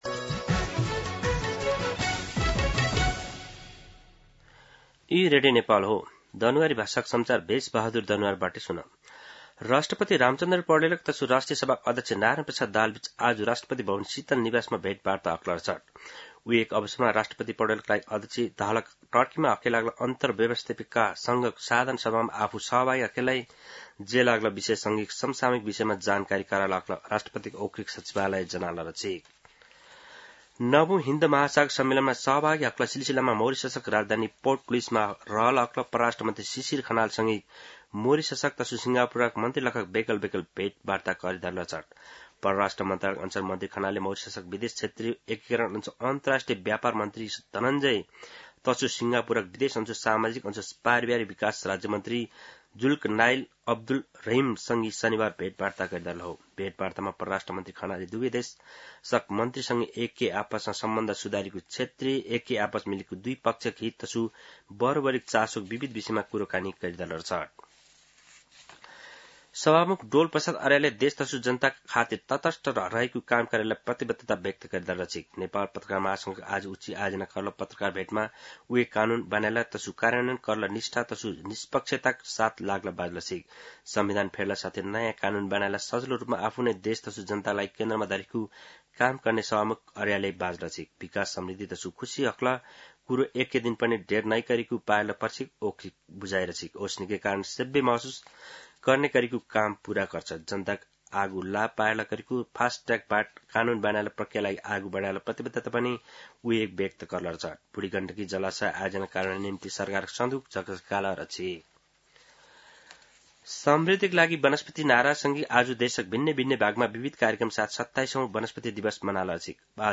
दनुवार भाषामा समाचार : २९ चैत , २०८२
Danuwar-News-29.mp3